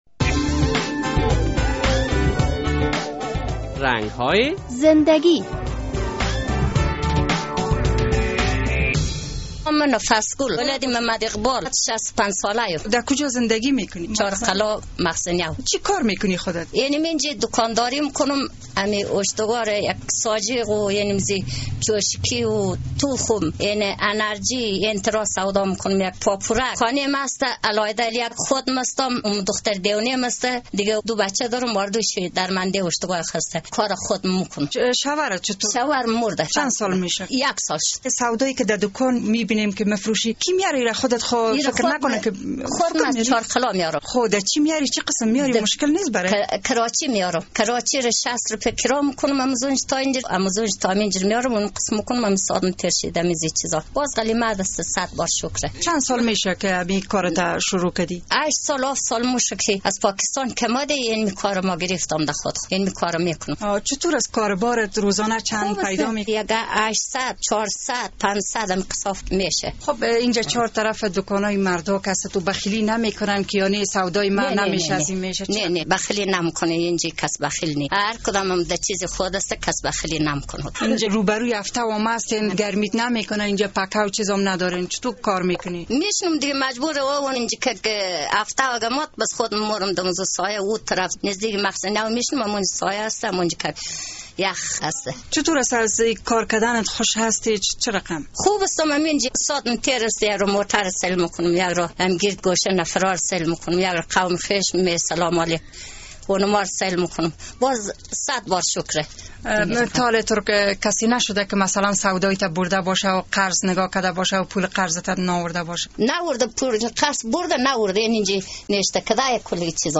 در این برنامهء رنگ های زندگی با یک زن کهن سال افغان صحبت شده است.